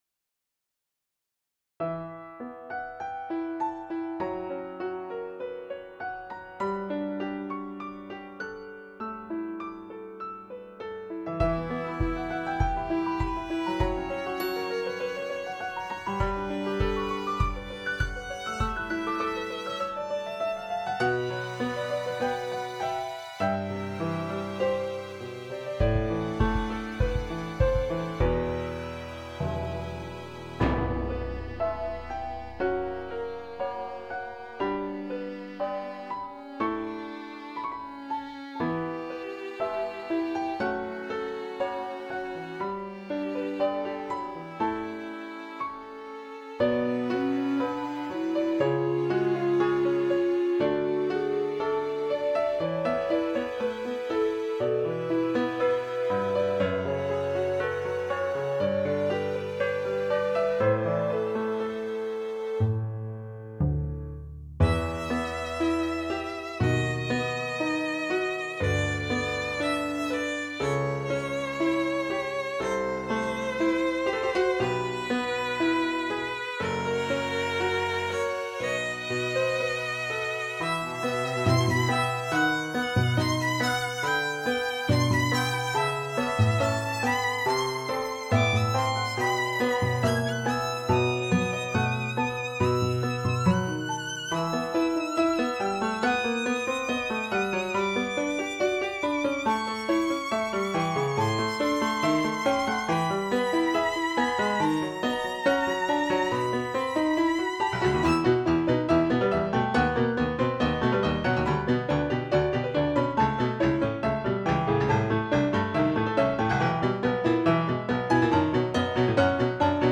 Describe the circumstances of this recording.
Also available in a lower quality, much smaller